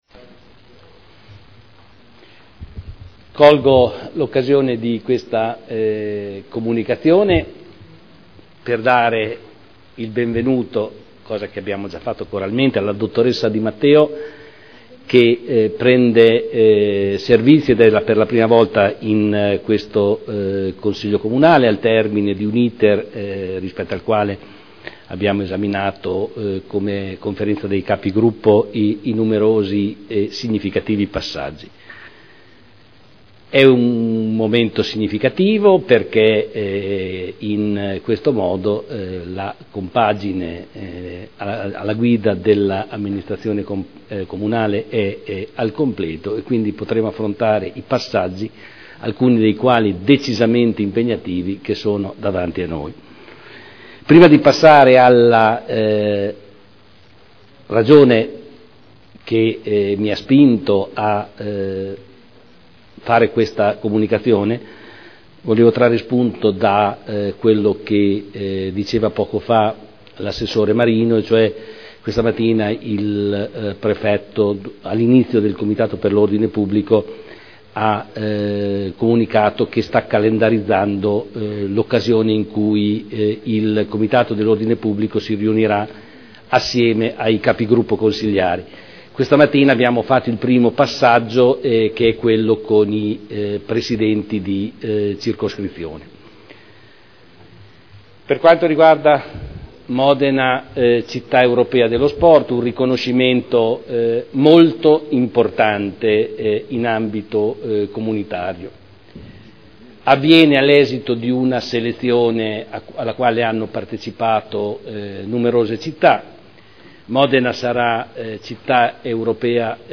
Seduta del 05/12/2011. Comunicazione su "European City of Sport 2013"